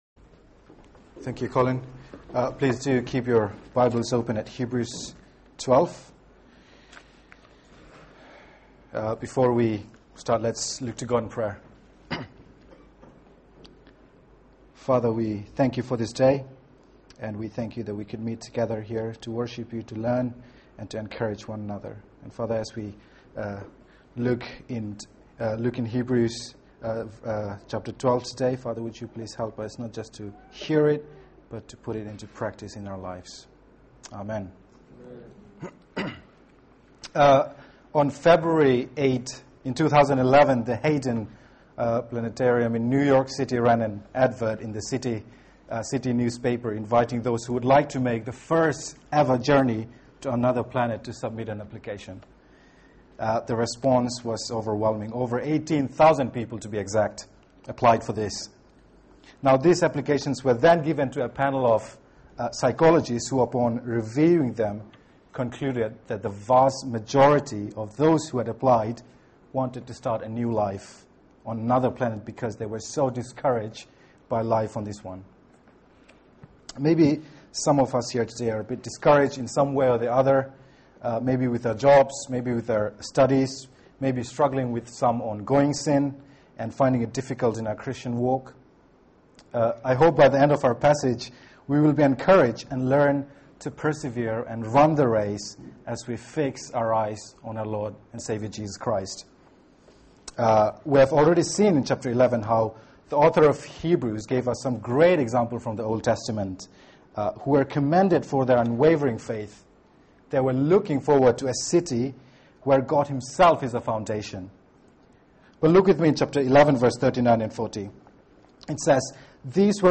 Media for 4pm Service on Sun 26th Aug 2012 16:00 Speaker
Series: Heroes of faith Theme: Hebrews 12:1-3 Sermon